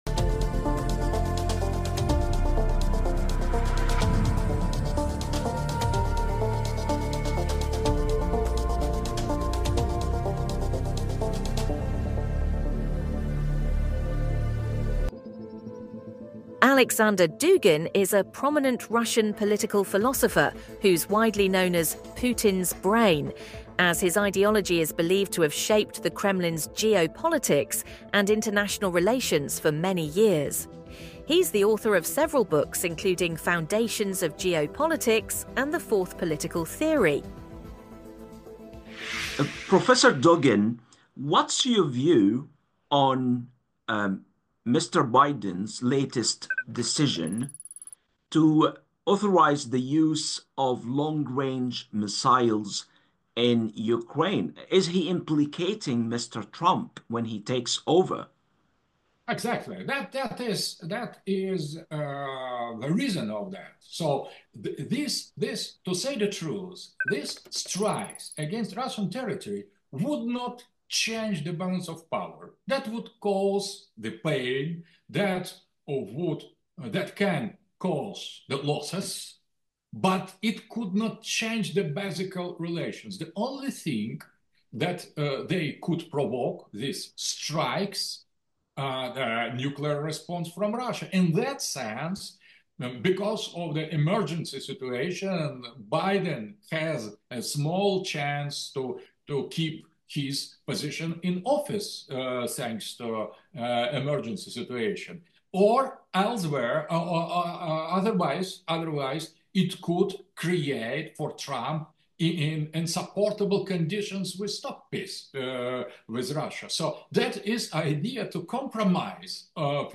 In an exclusive interview with Al Majalla, the Russian political philosopher explains his country's pivot to the Global South and why nothing will change Moscow's edge on the Ukrainian battlefield Source